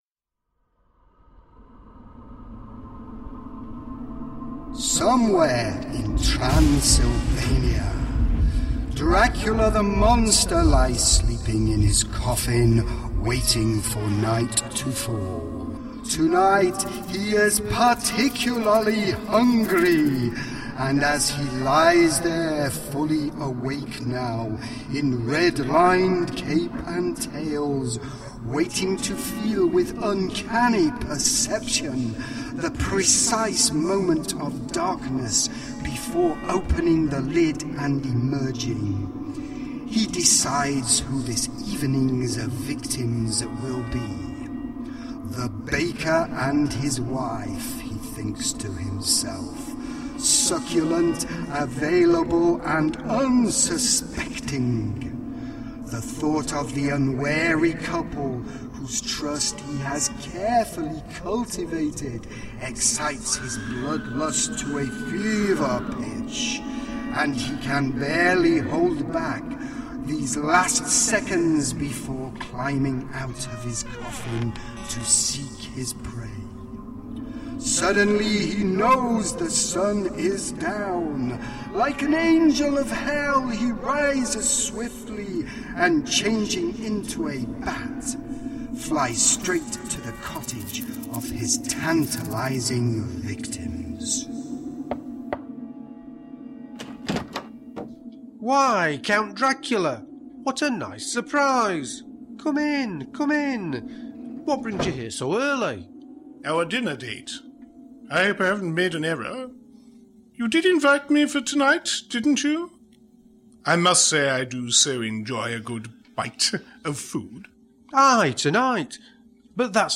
This comedy sketch comes from one of our radio shows in 2010, as we took the short comedy playlet “Count Dracula” by Woody Allen published in his 1971 book “Getting Even” and brought it to life in our own inimitable way.